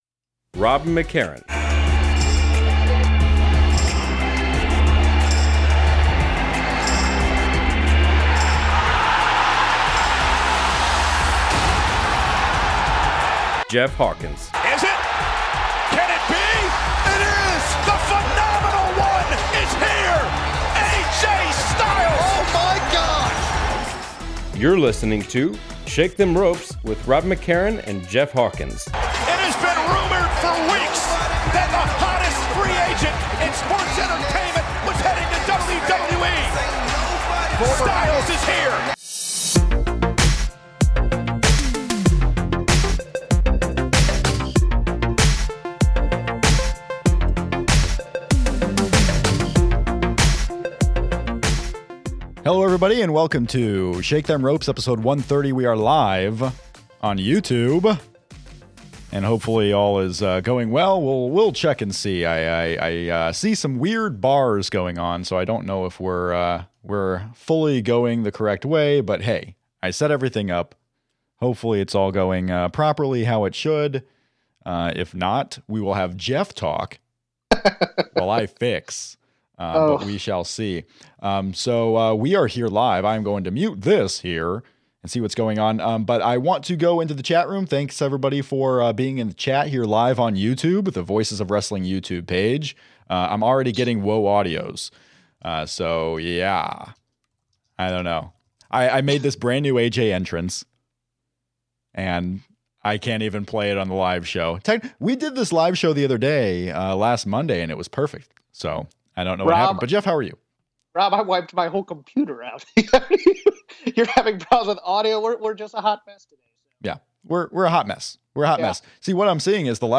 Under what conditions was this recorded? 130. WWE Royal Rumble 2016 Live Reaction